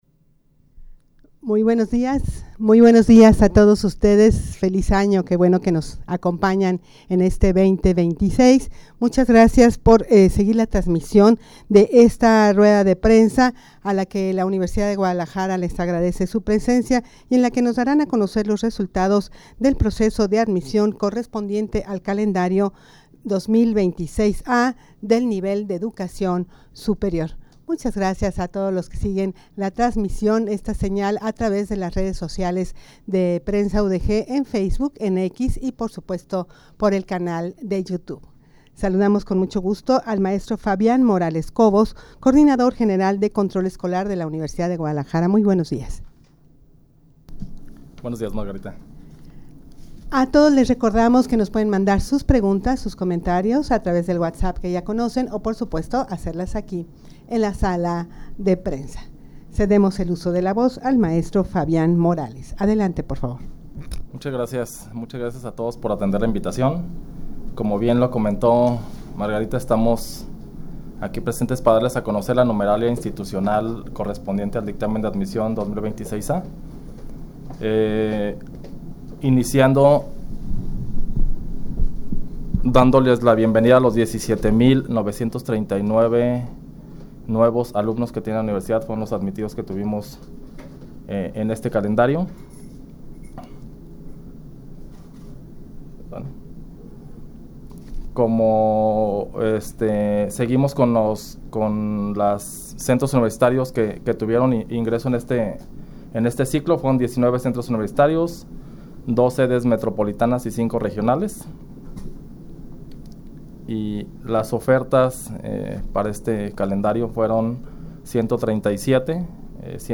rueda-de-prensa-para-dar-a-conocer-los-resultados-del-dictamen-de-admision-correspondiente-al-calendario-2026-a.mp3